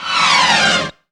SLIDE TRUMP.wav